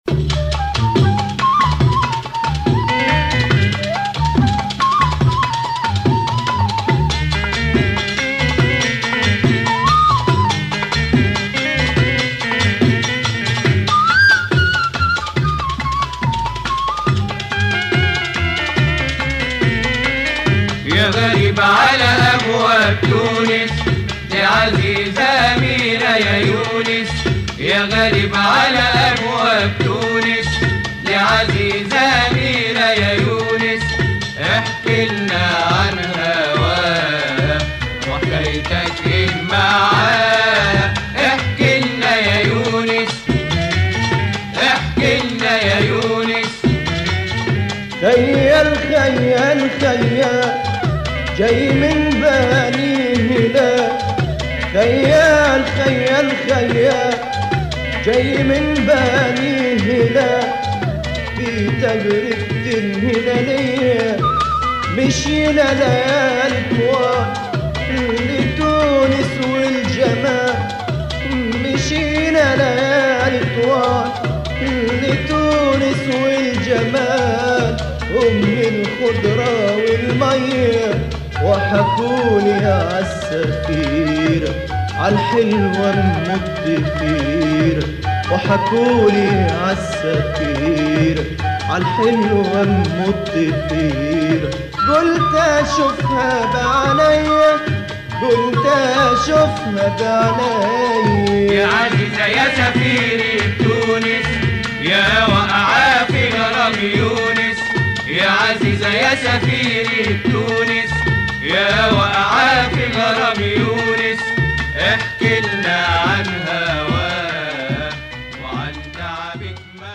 disco pop groove